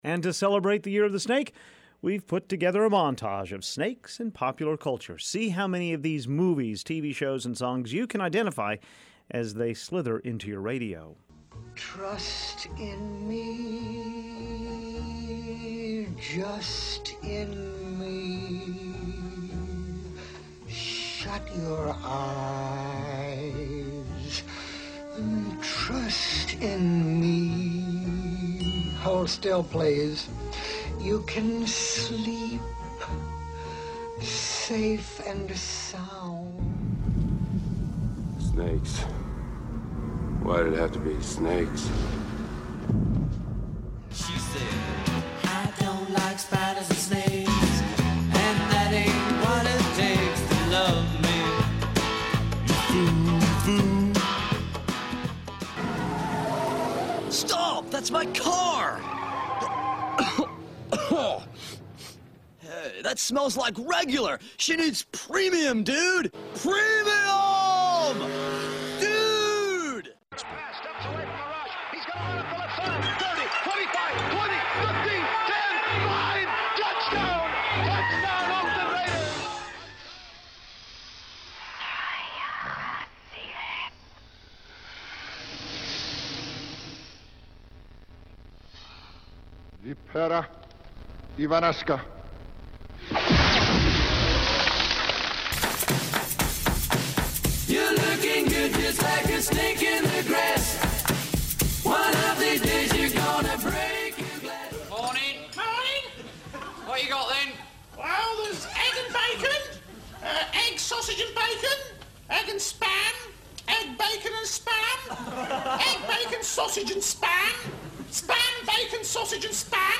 This is our list of items for this morning’s montage of snakes:
1. Kaa, the snake from The Jungle Book, sings a hypnotic tune.
3. Jim Stafford, now of Branson, sings one of his biggest hits, "Spiders and Snakes."
5. A highlight of Kenny “The Snake” Stabler running for a touchdown for the Oakland Raiders.
7. Electric Light Orchestra performing "Don’t Let Me Down."